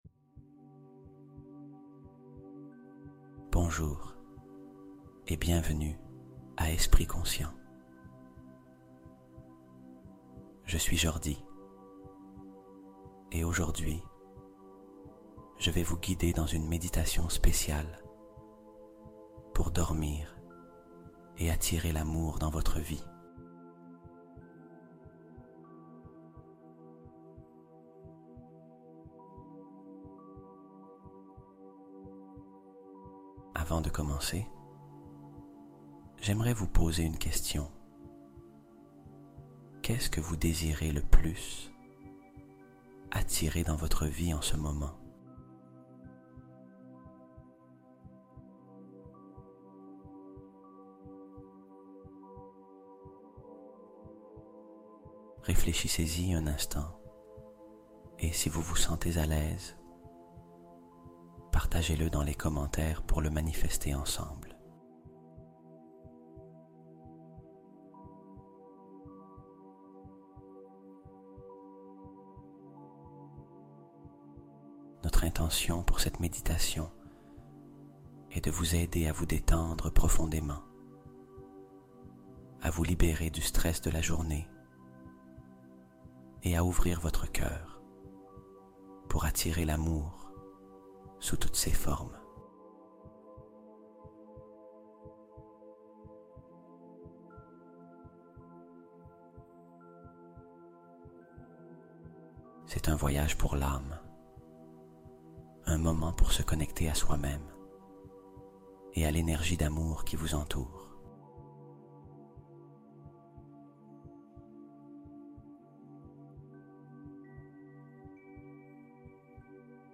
Énergie du Cœur : Méditation nocturne pour harmoniser ses relations